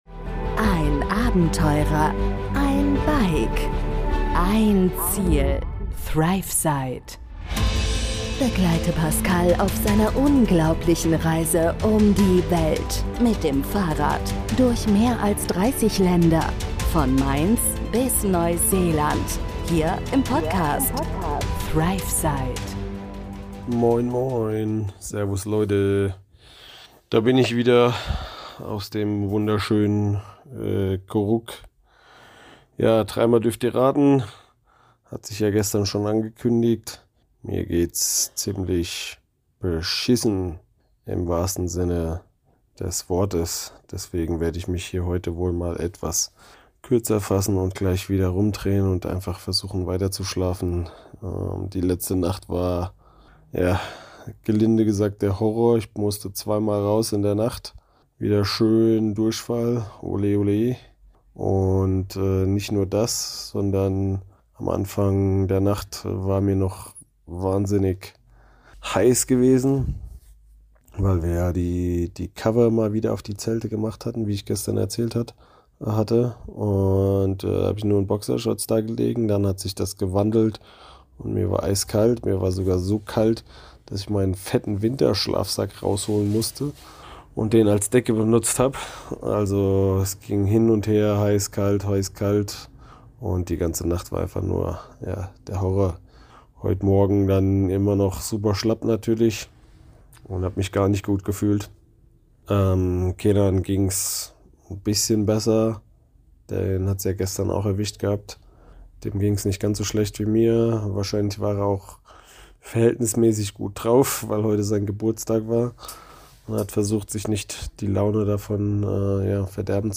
Diese Folge kommt live aus Koruk – und leider auch mit einem